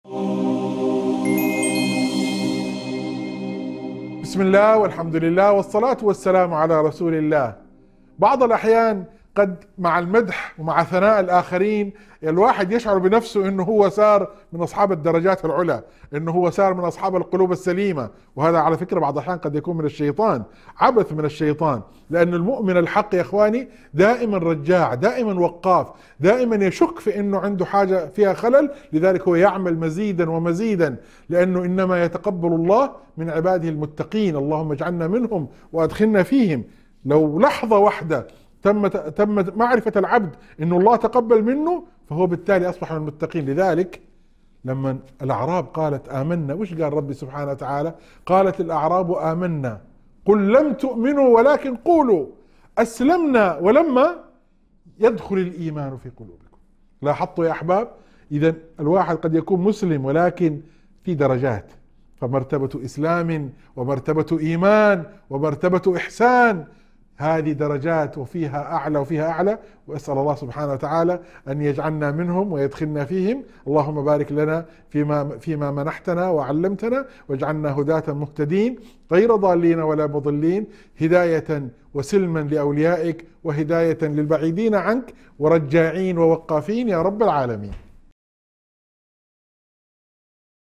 موعظة مؤثرة تتحدث عن درجات الإيمان واليقين، وتحذر من وساوس الشيطان التي تثني المؤمن عن العمل. تؤكد على أهمية الرجاء في الله والاستمرار في الطاعة، لأن القبول من الله تعالى هو غاية المؤمن الحقيقي.